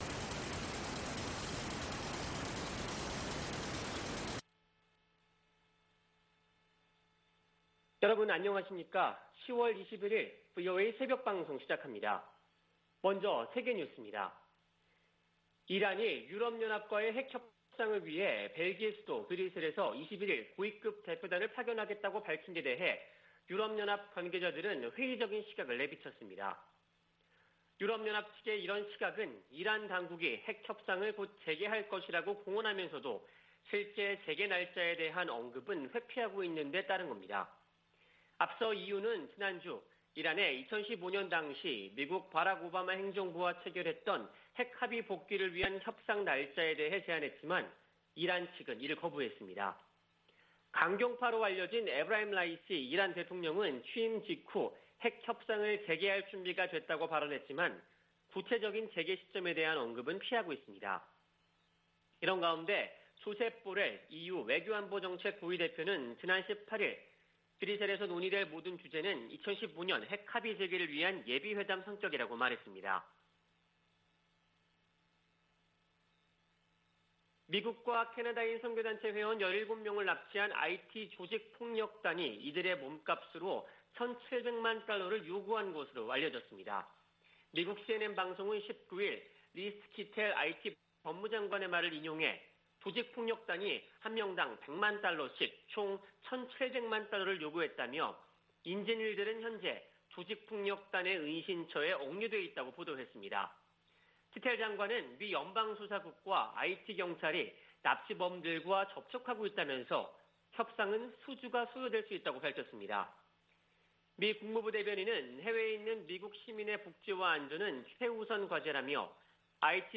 VOA 한국어 '출발 뉴스 쇼', 2021년 10월 21일 방송입니다. 미국 정부는 북한의 탄도미사일 발사에 우려하며 조속히 대화에 나설 것을 촉구했습니다. 유엔은 북한 미사일과 관련, 국제 의무를 준수하고 외교노력을 재개하라고 촉구했습니다.